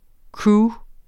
Udtale [ ˈkɹuː ]